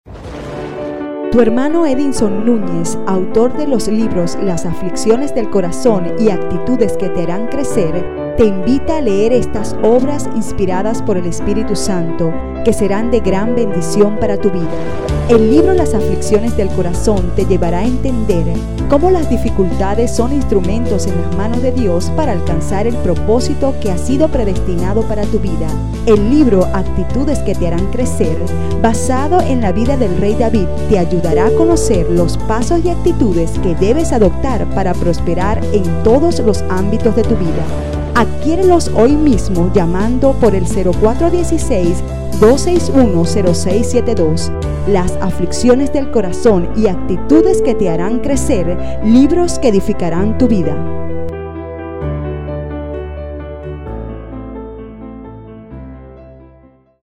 Voice off, Voice over, productor.
Sprechprobe: Werbung (Muttersprache):